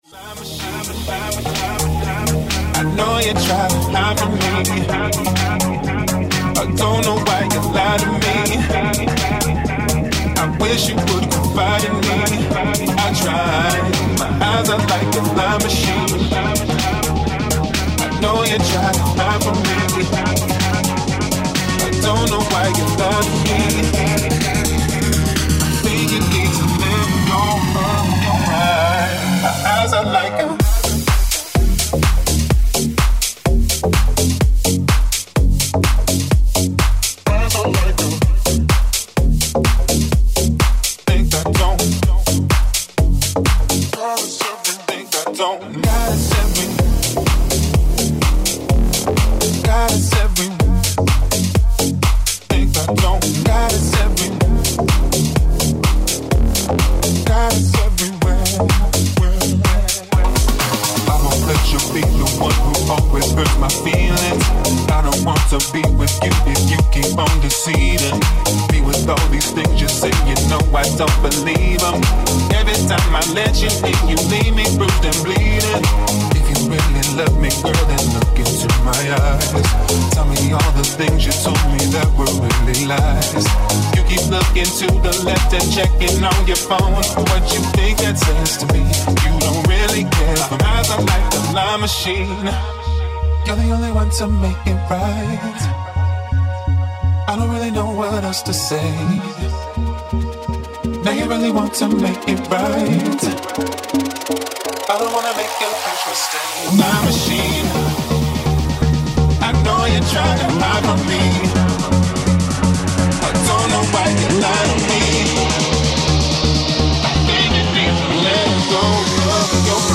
Live Sets and Mixes
Electronic Music